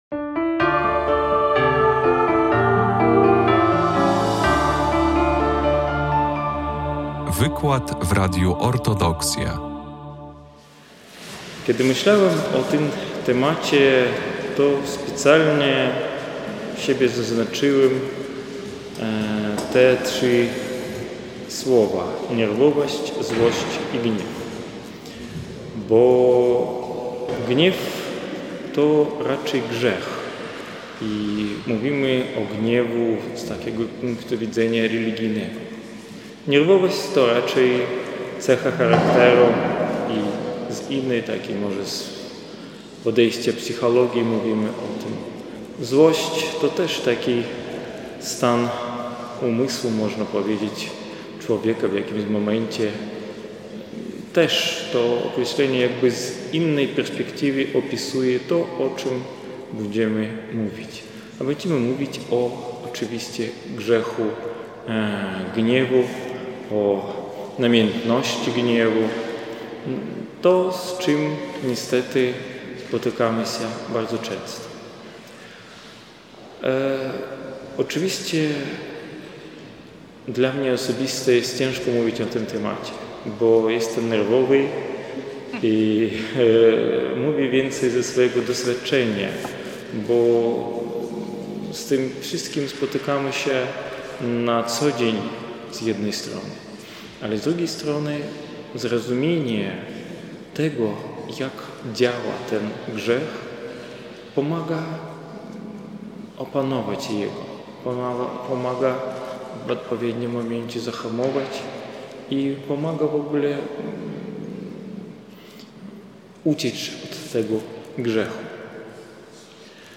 w parafii św. Jerzego Zwycięzcy w Białymstoku
wykład